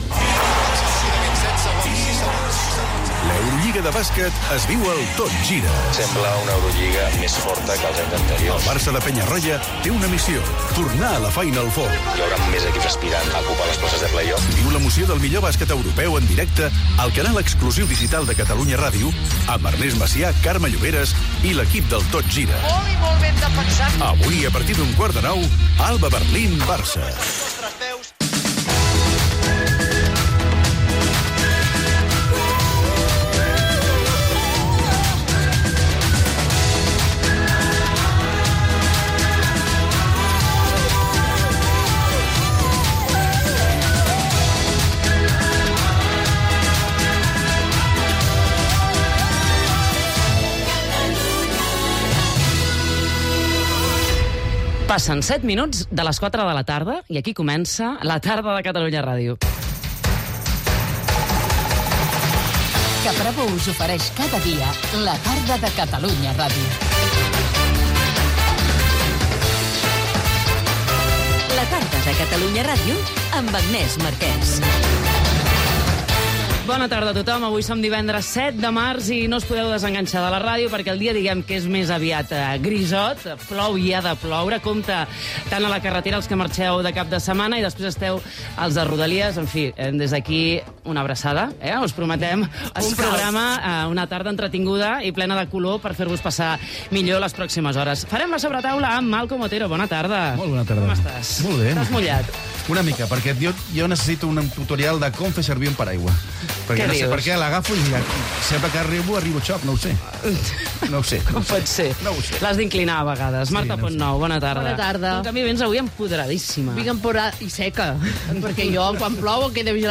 Promoció de l'Eurolliga de bàsquet, indicatiu de la ràdio, hora, careta, data, presentació del programa
Entreteniment